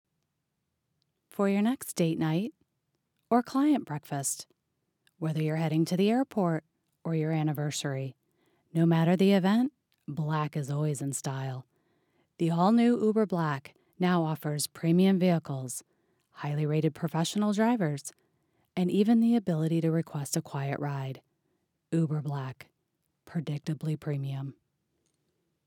Alto: calm, soothing, foreboding.
Midwestern
Middle Aged
Soothing voice for Calm app, guided meditation, etc. Foreboding tone for True Crime or Documentaries.